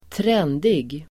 Ladda ner uttalet
trendig.mp3